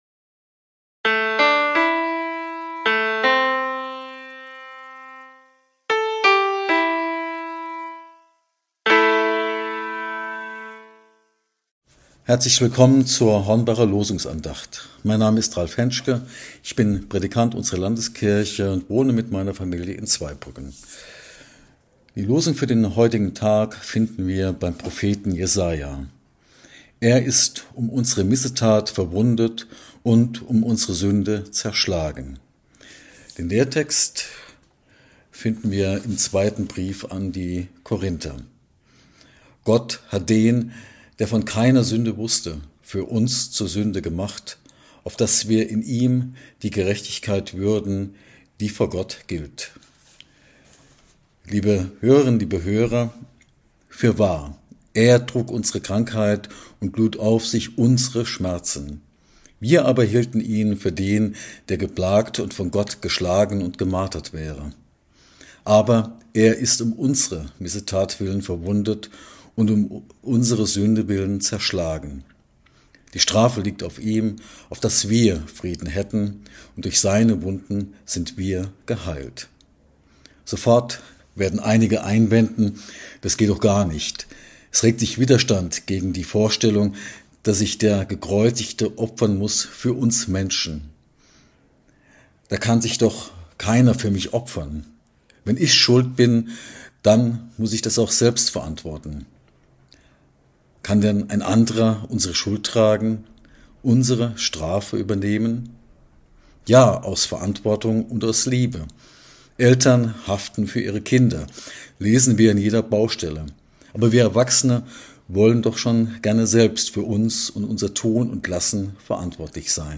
Losungsandacht für Samstag, 15.01.2022